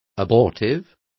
Complete with pronunciation of the translation of abortive.